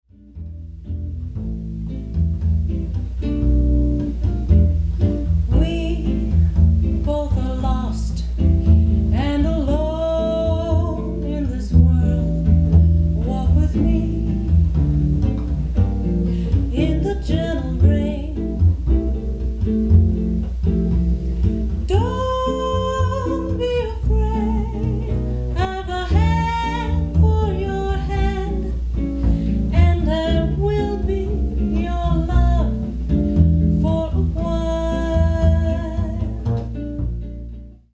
bass.
guitar